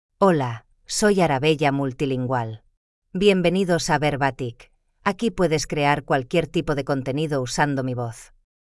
Arabella MultilingualFemale Spanish AI voice
Arabella Multilingual is a female AI voice for Spanish (Spain).
Voice sample
Listen to Arabella Multilingual's female Spanish voice.
Arabella Multilingual delivers clear pronunciation with authentic Spain Spanish intonation, making your content sound professionally produced.